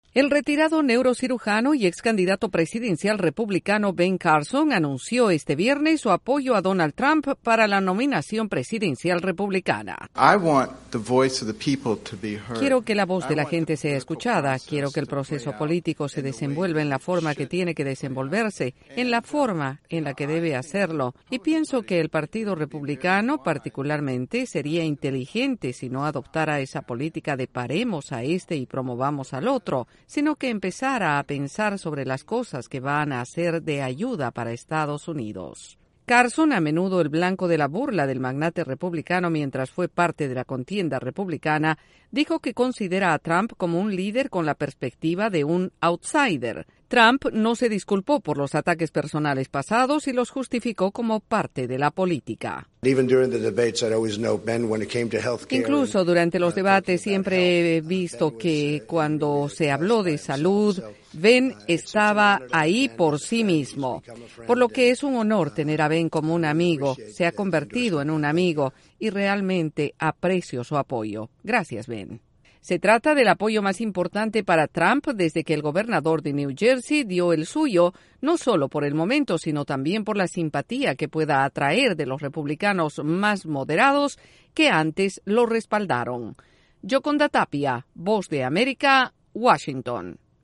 El neurocirujano Ben Carson decidió apoyar a Donald Trump en la carrera electoral hacia la Casa Blanca. Desde la Voz de América en Washington DC informa